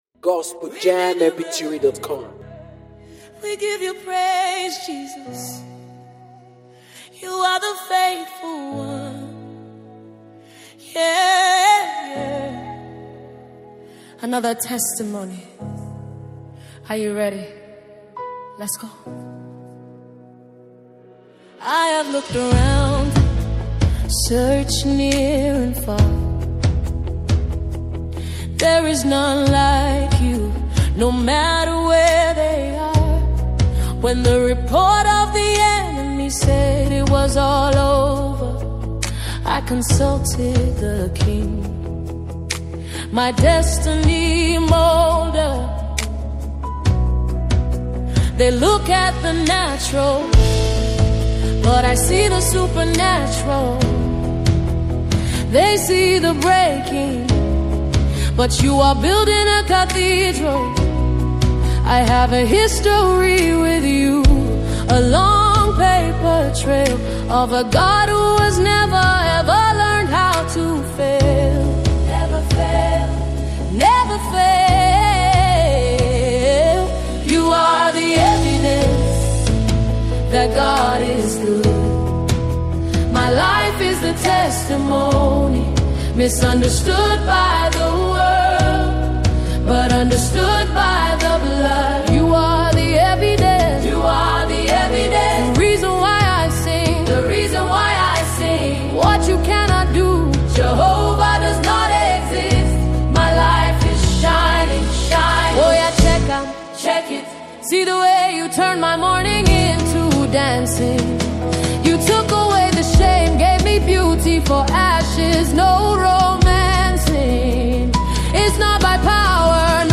a powerful and faith-filled gospel anthem
With uplifting lyrics and energetic praise